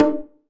9LOBONGO.wav